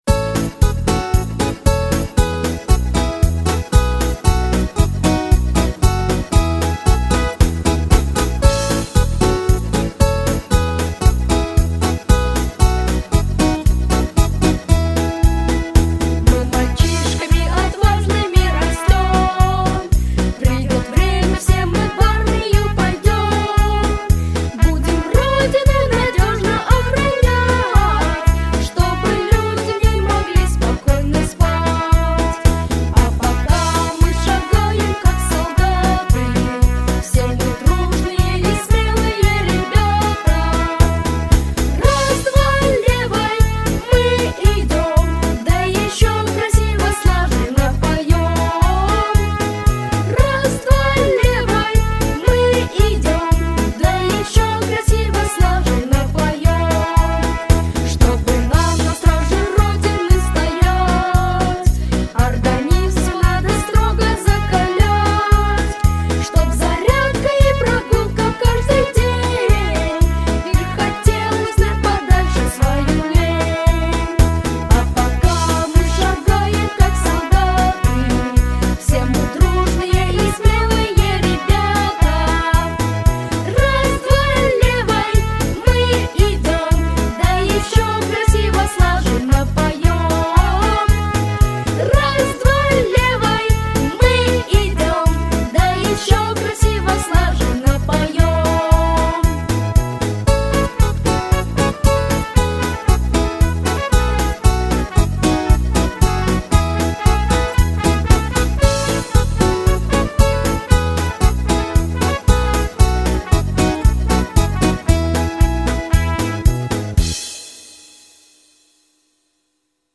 праздничная песня